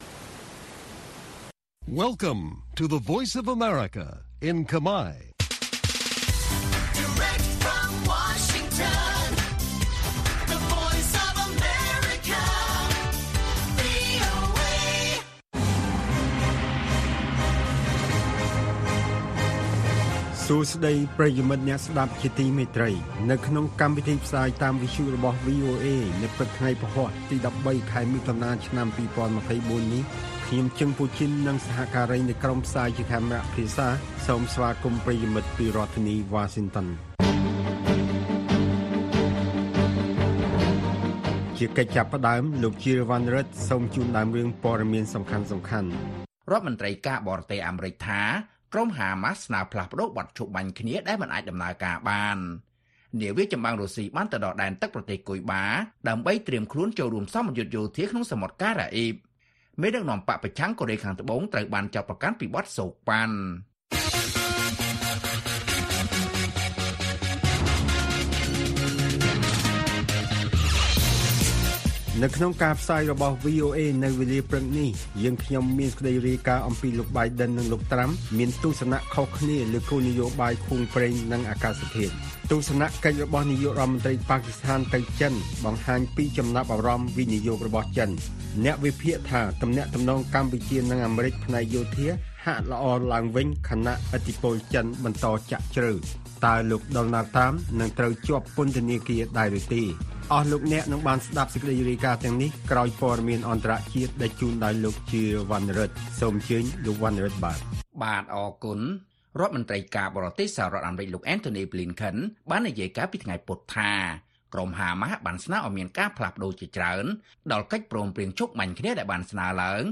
Listen Live - កម្មវិធីវិទ្យុពេលព្រឹក - វីអូអេ - VOA Khmer